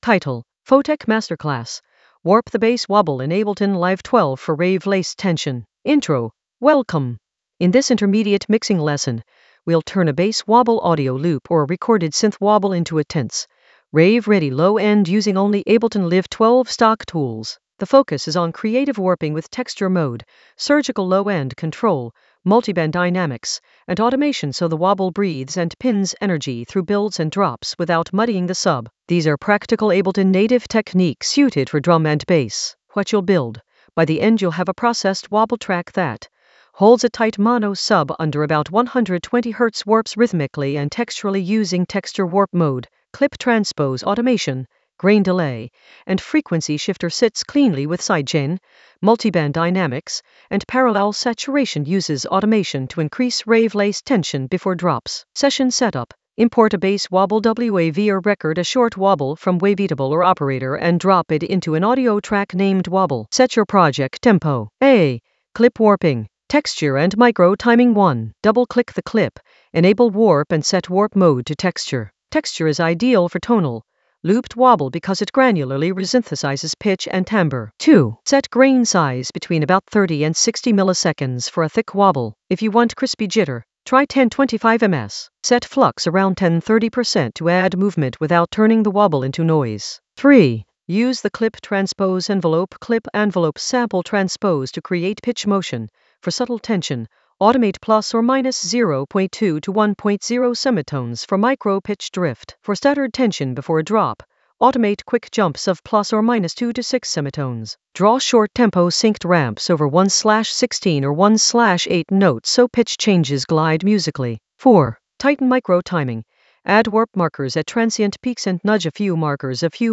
An AI-generated intermediate Ableton lesson focused on Photek masterclass: warp the bass wobble in Ableton Live 12 for rave-laced tension in the Mixing area of drum and bass production.
Narrated lesson audio
The voice track includes the tutorial plus extra teacher commentary.